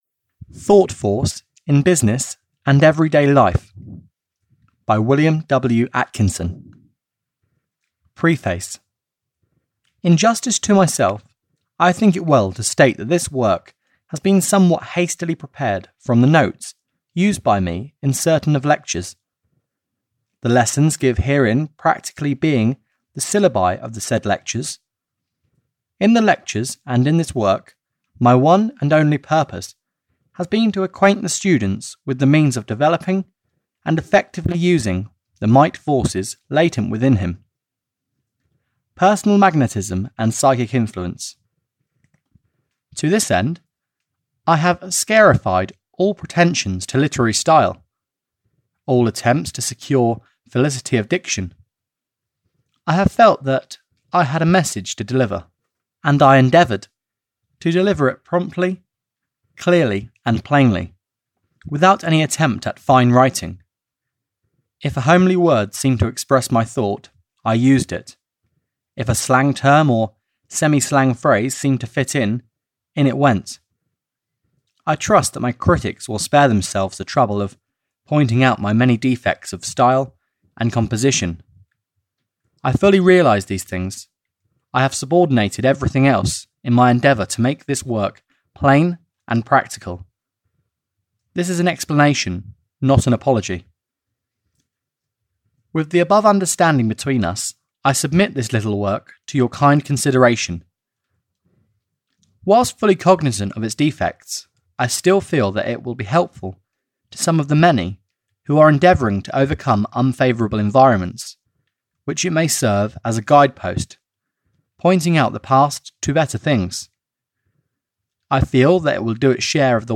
Audio knihaThought Force In Business and Everyday Life (EN)
Ukázka z knihy